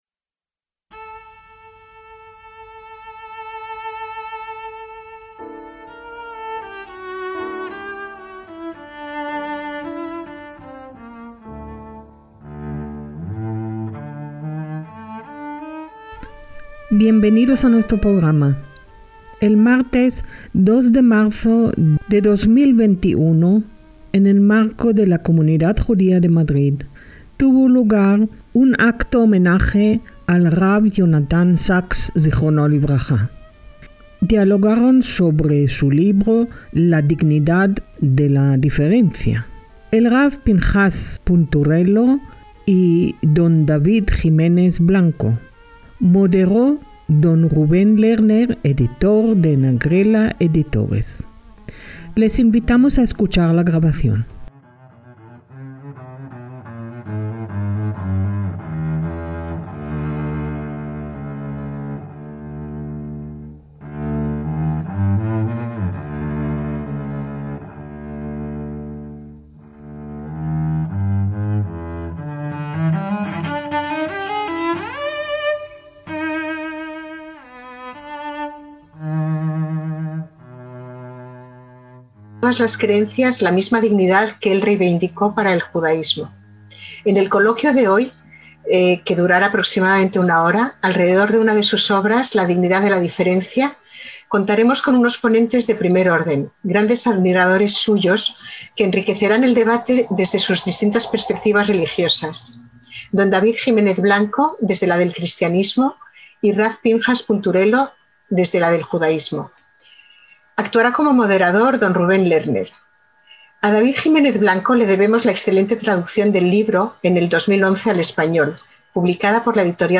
El 2 de marzo de 2021 tuvo lugar, de forma telemática, un homenaje a rab Jonathan Sacks Z'L, organizado por la Comunidad Judía de Madrid